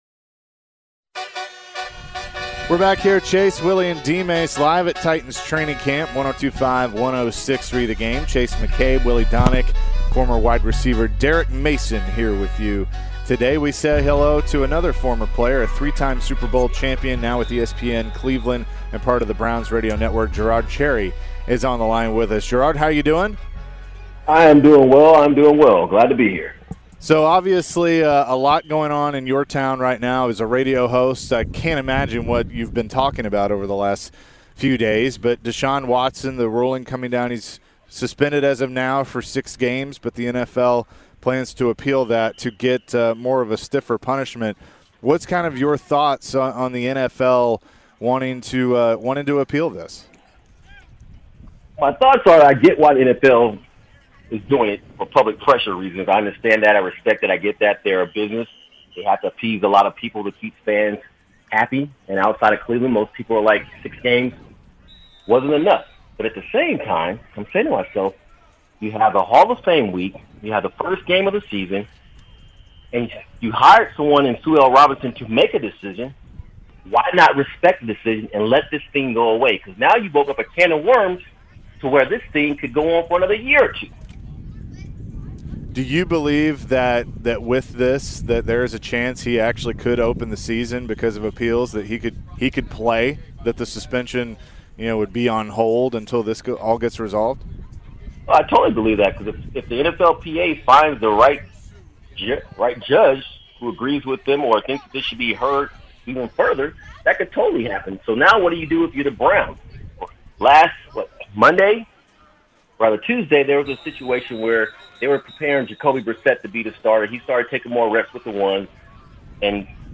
Jerod Cherry Full Interview (08-04-22)